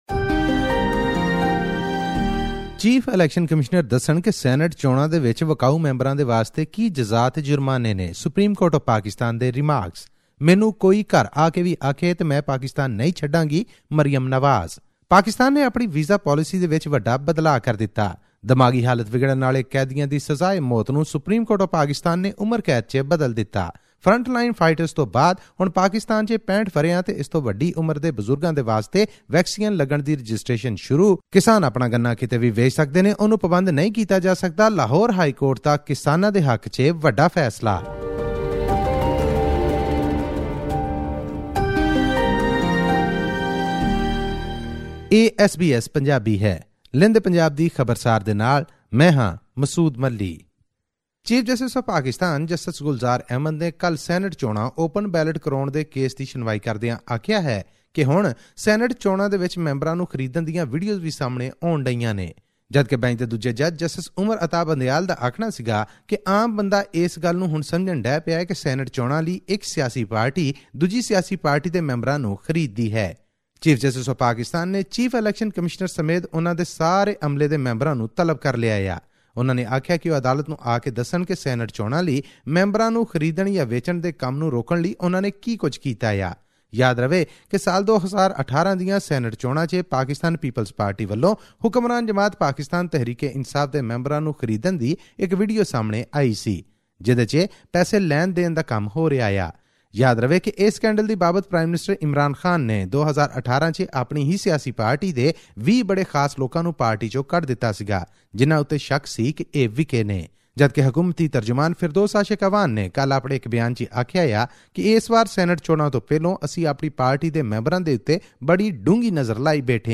audio report